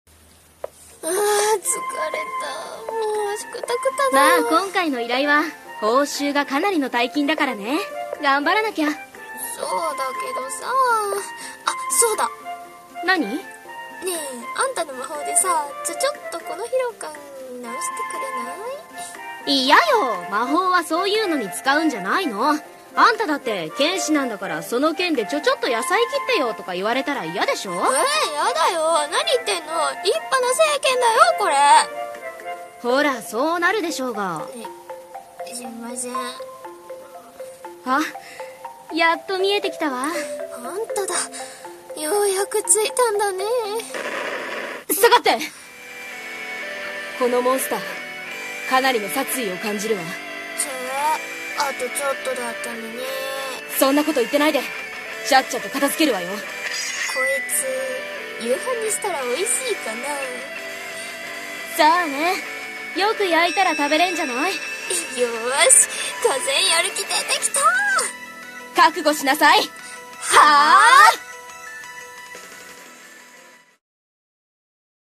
【コラボ声劇】魔法使いと剣士の冒険